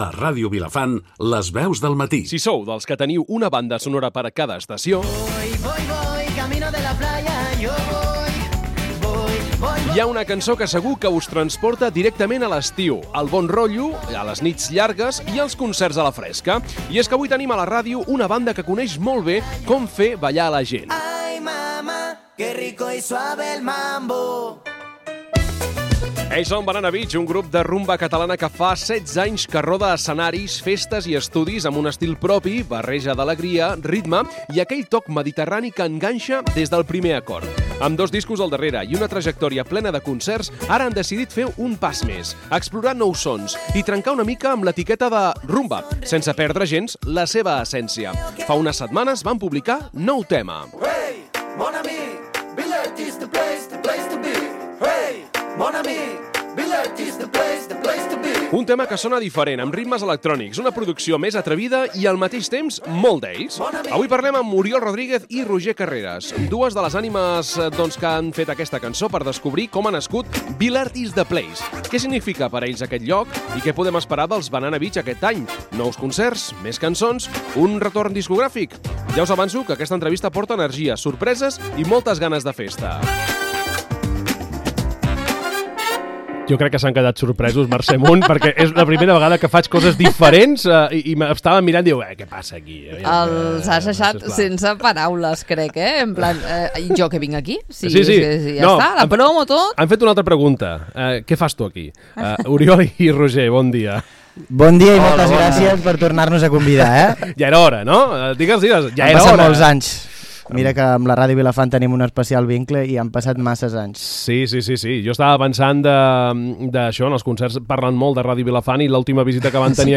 Una entrevista plena de música, records i noves energies!